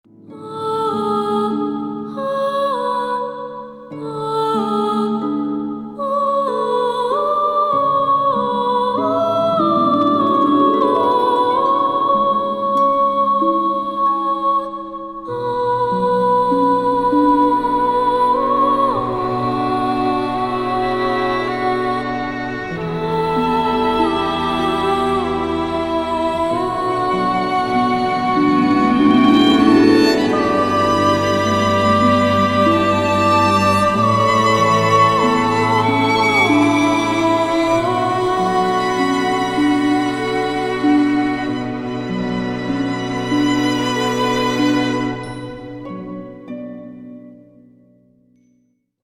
женский вокал
спокойные
арфа